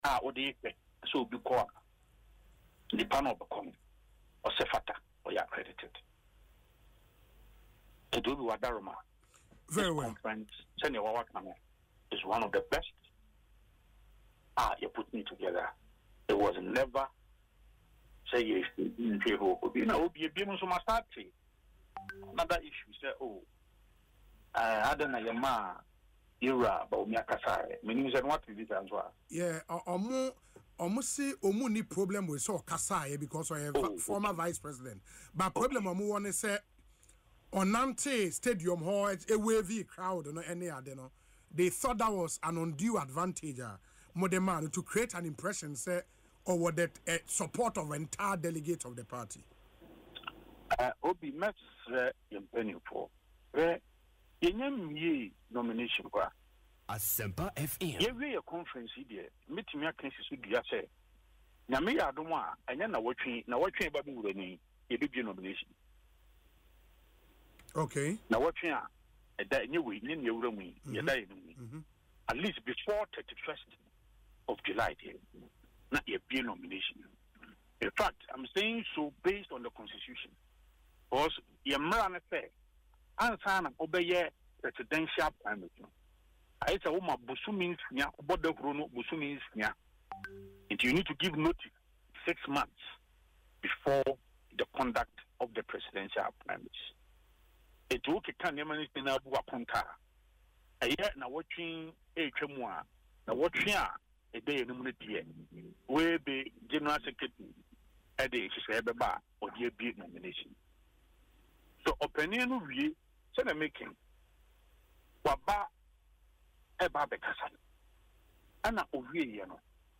during an interview on Asempa FM’s Ekosii Sen following the party’s successful National Delegates Conference held on Saturday